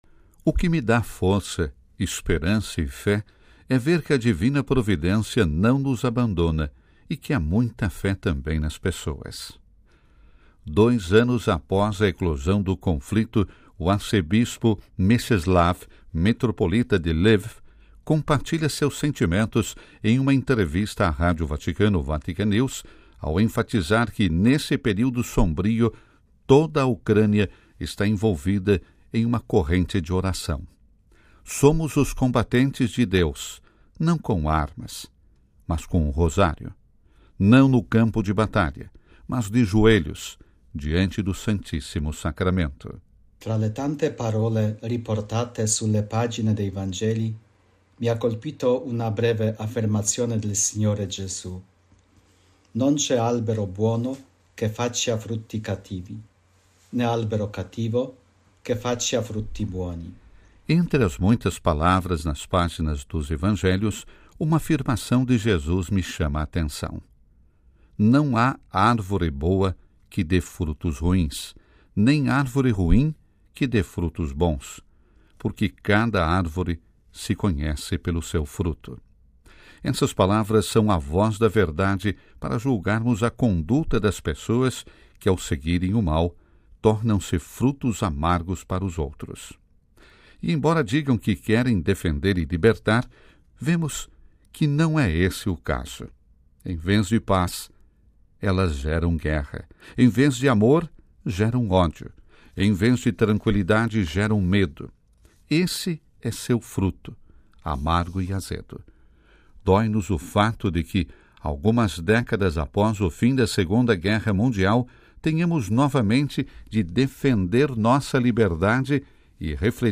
Dois anos após a eclosão do conflito, o arcebispo Mieczysław Mokrzycki, metropolita de Lviv, compartilha seus sentimentos em uma entrevista à Rádio Vaticano – Vatican News, ao enfatizar que, neste período sombrio, toda a Ucrânia está envolvida em uma corrente de oração.